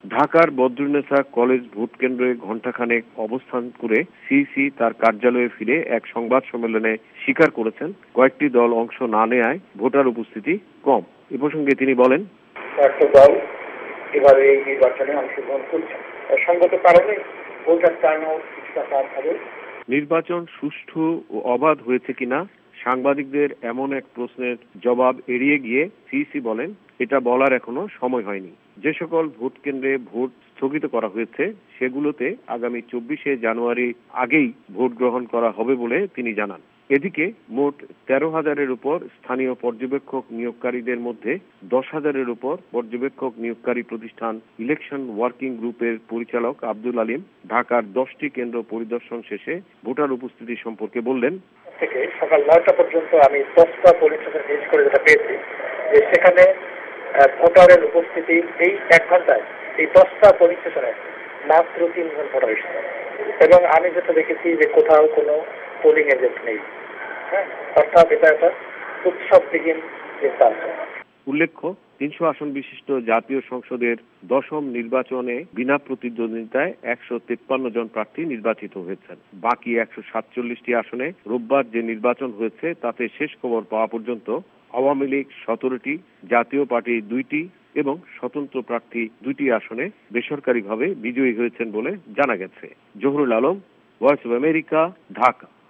বাংলাদেশে দশম জাতীয় সংসদ নির্বাচনের ভোট হলো পাঁচ জানুয়ারী - VOA সংবাদদাতাদের রিপোর্ট